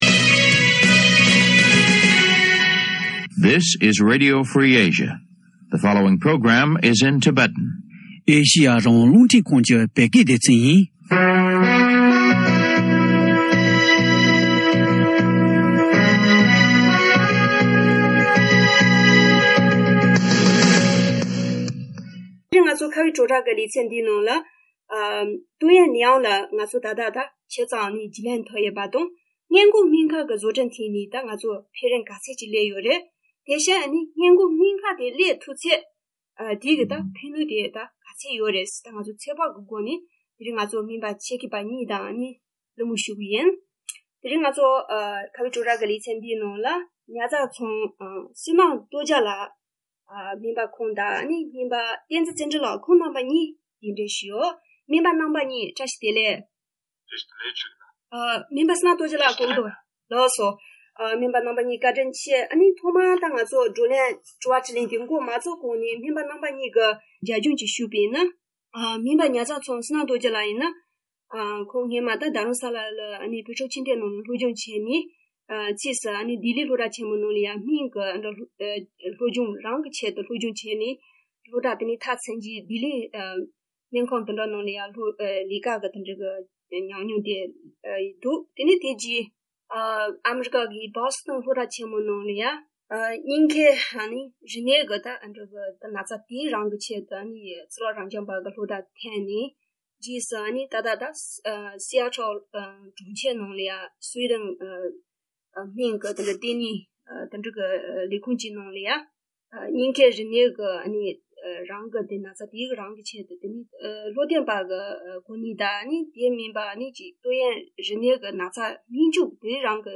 སྨན་པ་ཆེད་མཁས་པ་གཉིས་